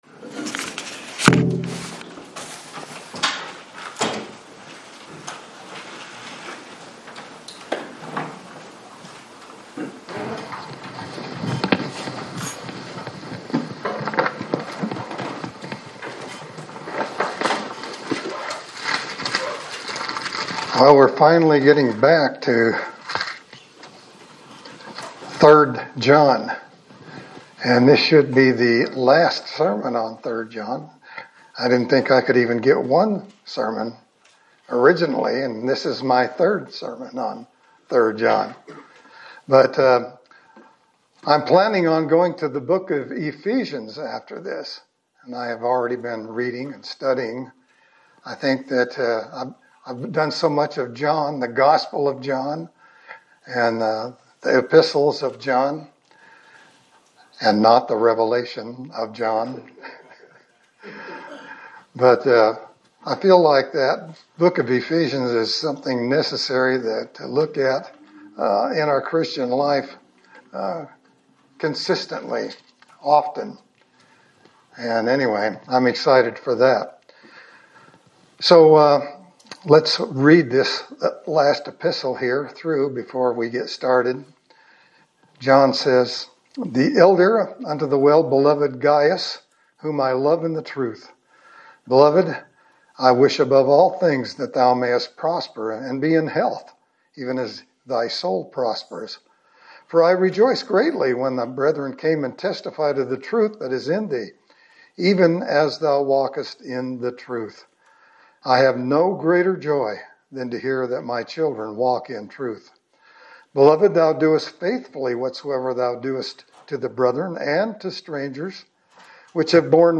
Sermon for January 4, 2026
Service Type: Sunday Service